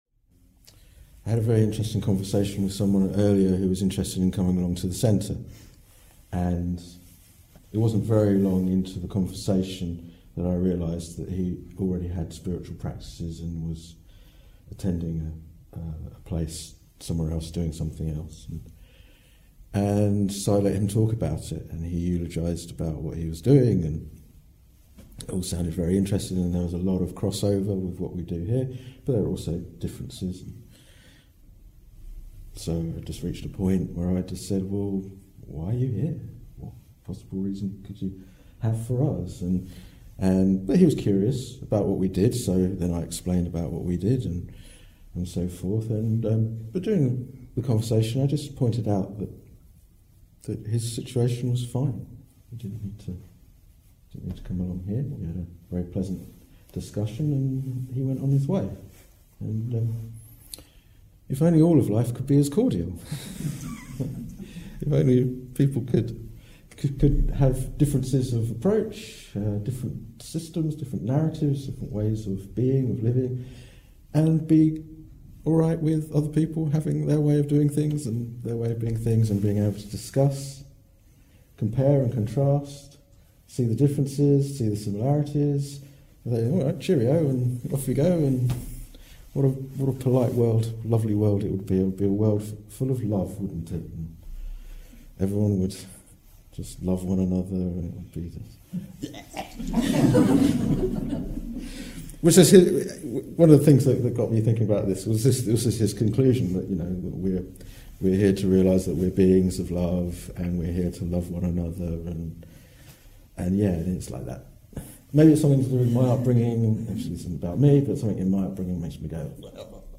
This talk was given in October 2018.